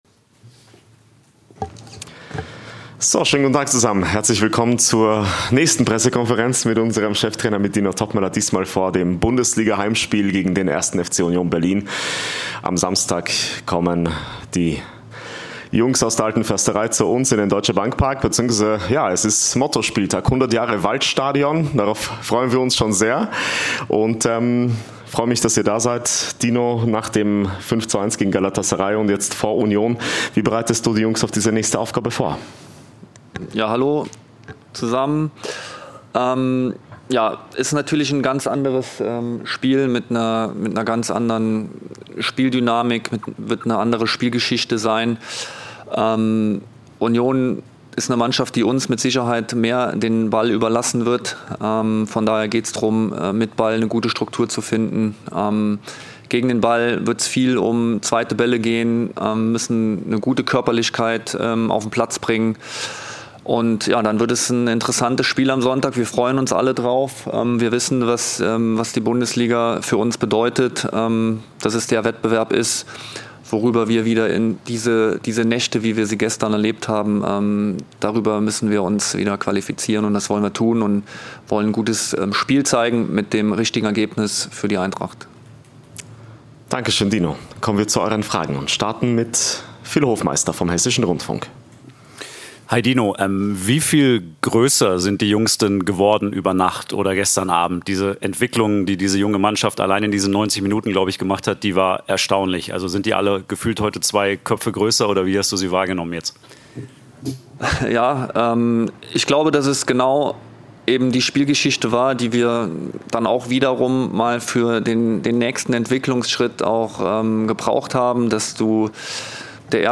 I Die Pressekonferenz vor 1. FC Union Berlin